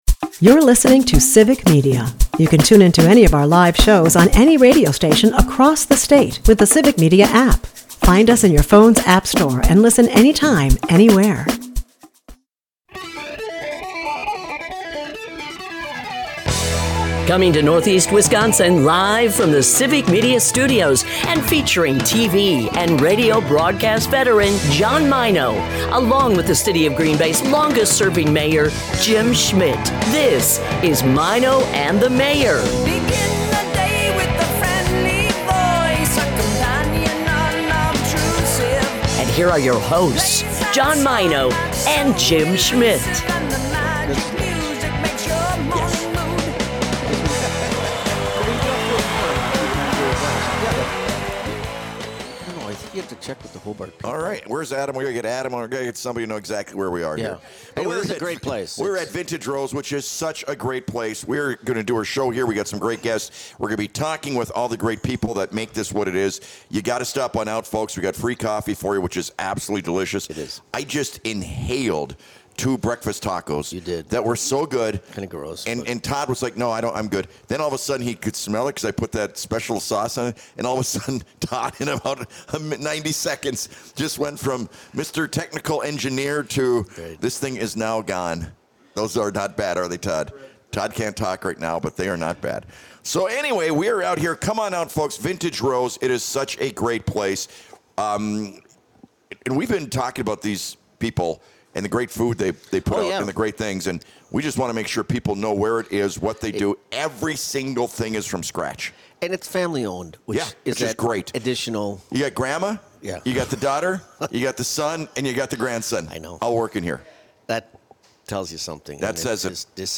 This guy brings the ENERGY!!!